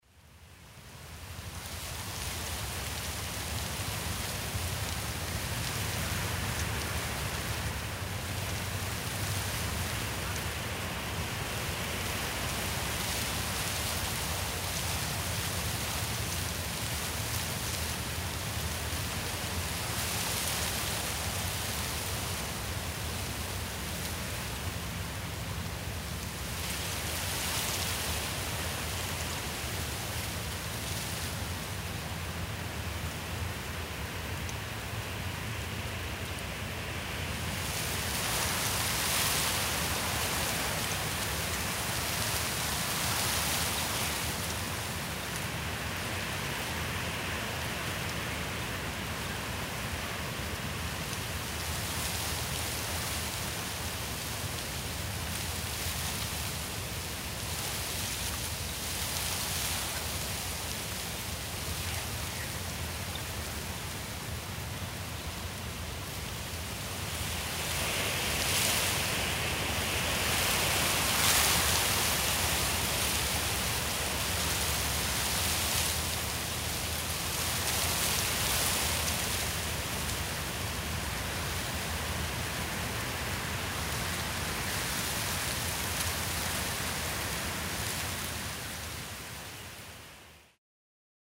Звуки травы
Шепот ветра в колышущейся траве